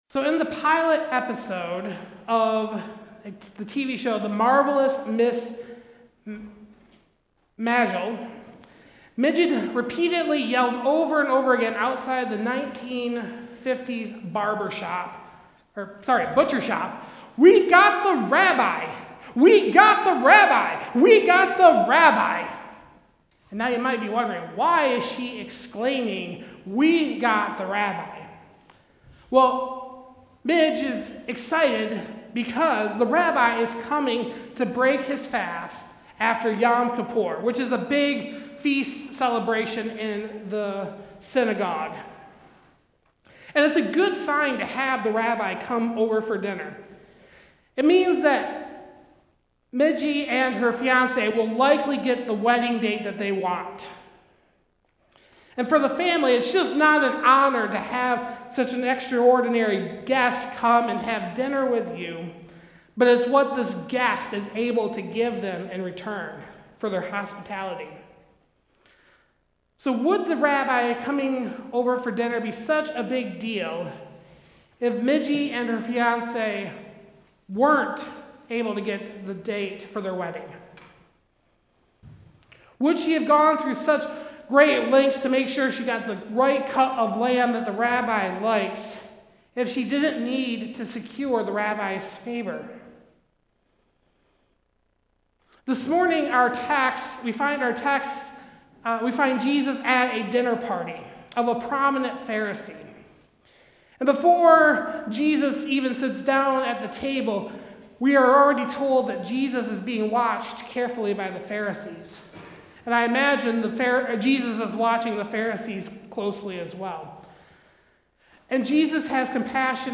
Passage: Luke 14: 7-24 Service Type: Sunday Service